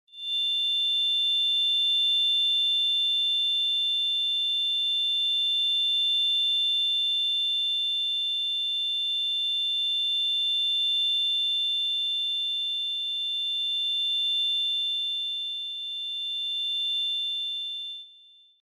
acouphene.mp3